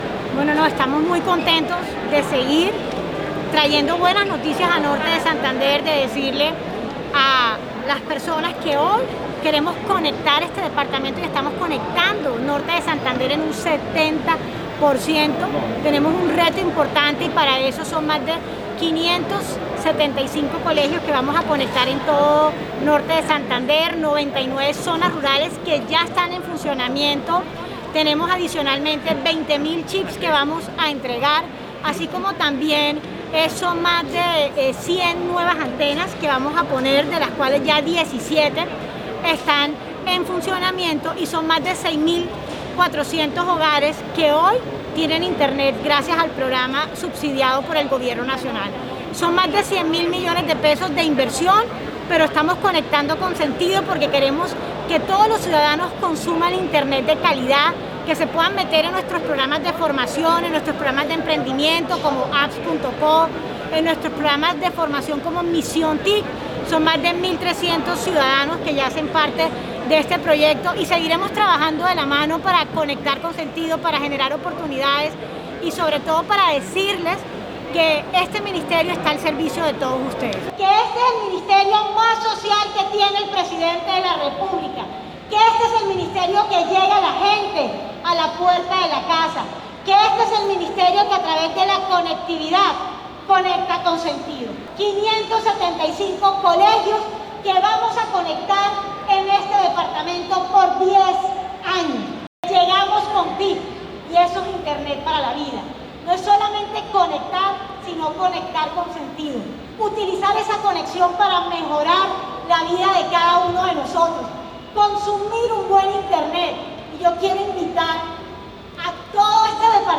Declaraciones de Karen Abudinen, ministra TIC.
audio-testimonio-ministra.mp3